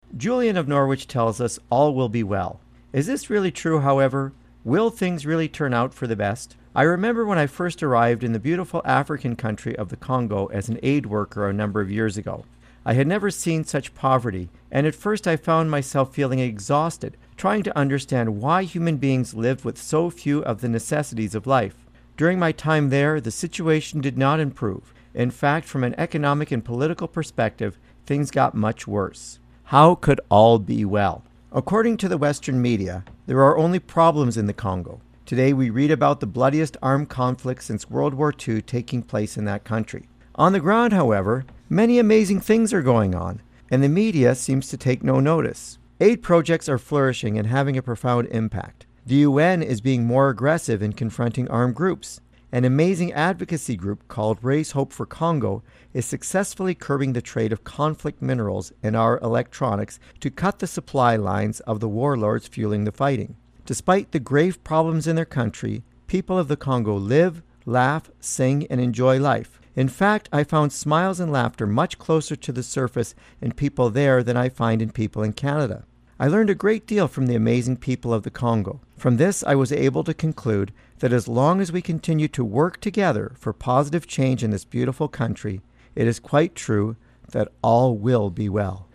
Type: Commentary
241kbps Stereo